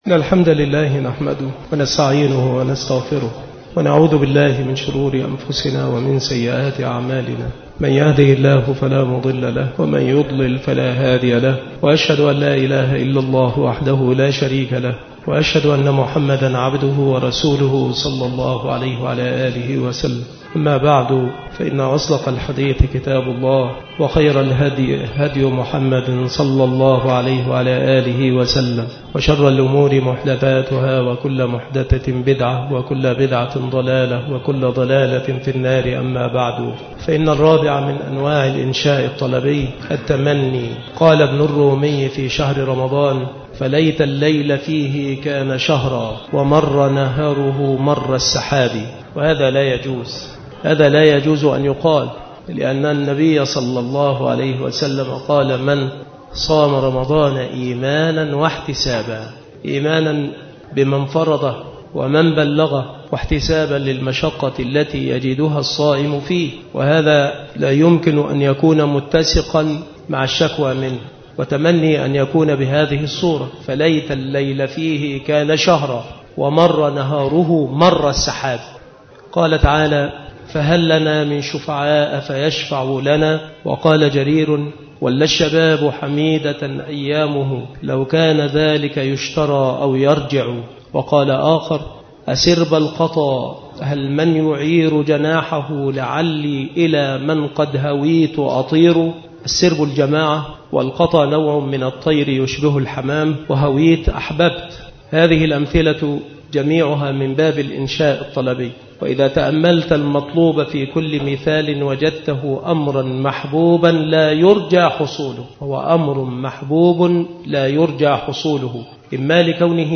مكان إلقاء هذه المحاضرة بالمسجد الشرقي بسبك الأحد - أشمون - محافظة المنوفية - مصر عناصر المحاضرة : من أنواع الإنشاء الطلبي: التمني. أمثلة على التمني. ما هو التمني؟ تمرينات. من أنواع الإنشاء الطلبي: النداء. أمثلة على النداء. أدوات النداء. قواعد.